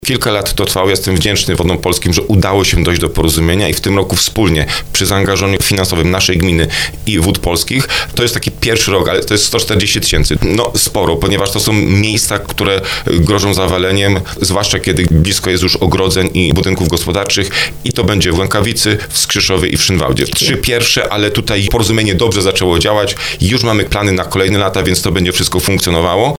O planowanych działaniach mówił w programie Słowo za Słowo wójt Marcin Kiwior.